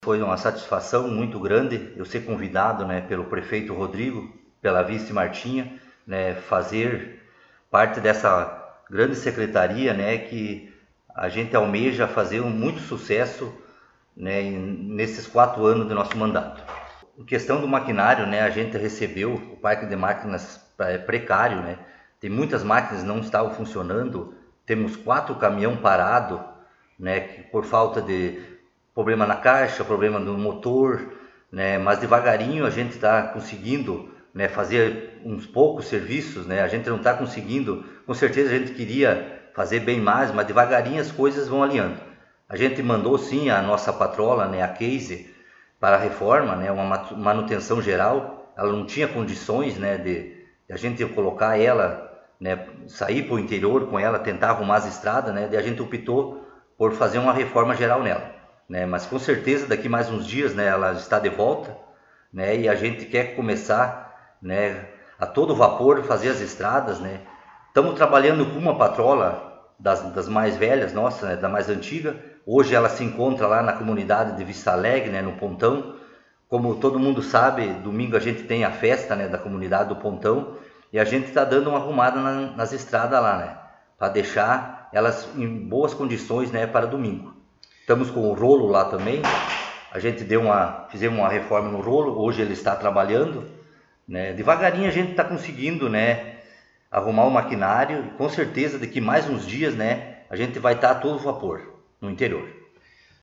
Secretário Municipal de Obras concedeu entrevista
No dia de ontem ( 30 ) o Colorado em Foco esteve no Parque de Máquinas para conversar com o secretário Aloísio e saber como está a situação do parque, dos maquinários e trabalhos.